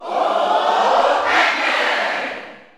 Crowd cheers (SSBU) You cannot overwrite this file.
Pac-Man_Cheer_German_SSBU.ogg.mp3